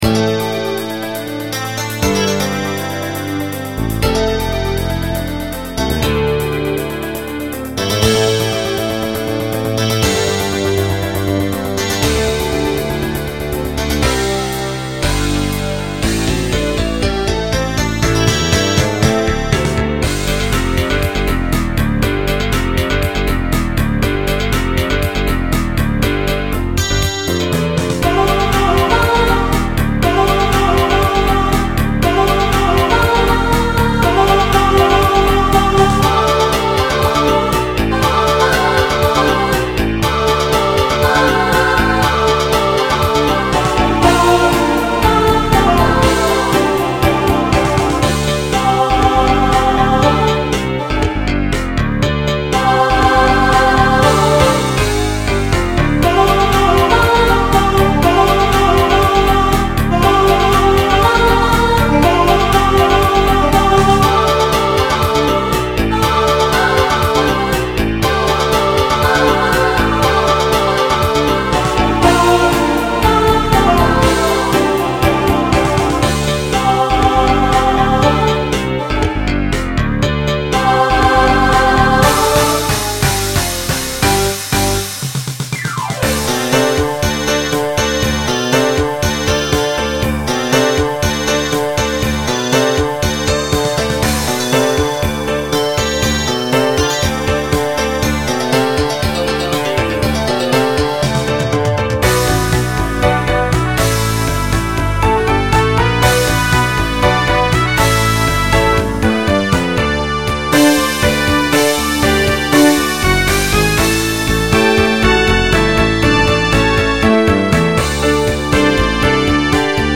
Voicing SSA Instrumental combo Genre Rock